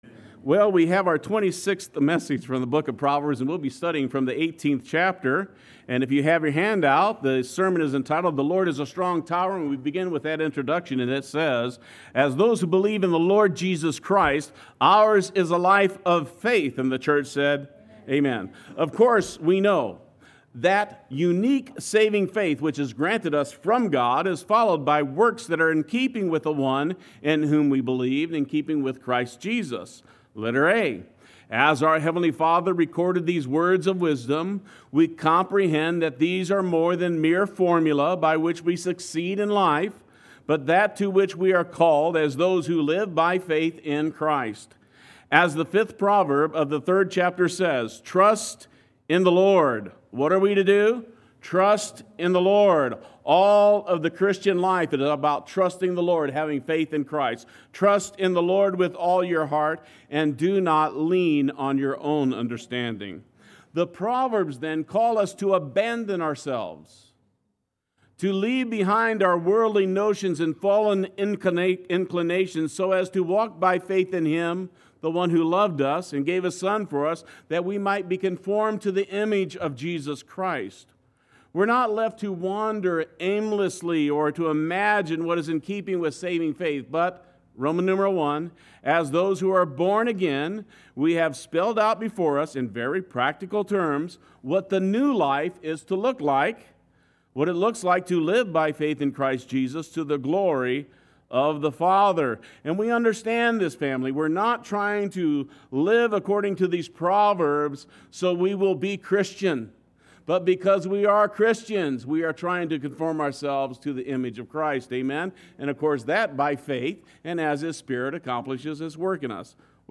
Play Sermon Get HCF Teaching Automatically.
The Lord is a Strong Tower Sunday Worship